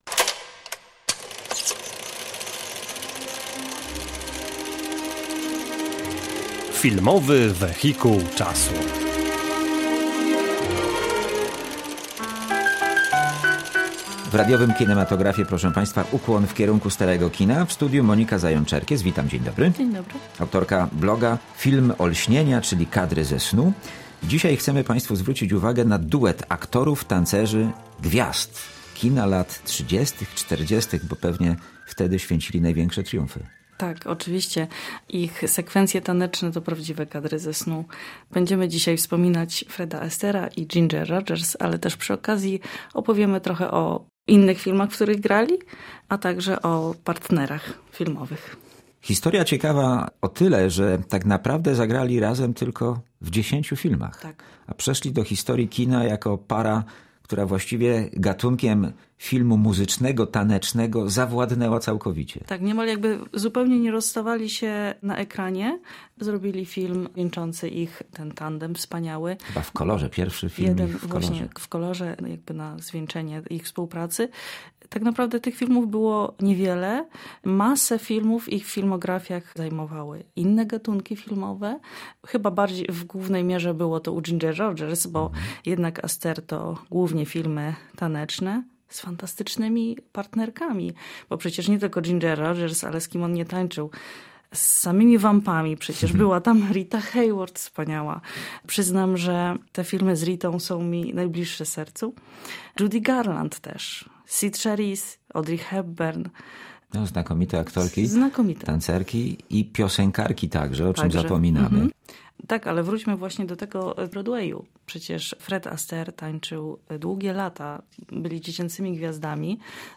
W „Filmowym wehikule czasu” rozmowa o wyjątkowej parze aktorów – tancerzy Fredzie Astairze i Ginger Rogers. Byli partnerami tanecznymi w 10 filmach. Astaire i Rogers po raz pierwszy wystąpili razem w filmie ‘’Lot do Rio’’ z 1933 roku.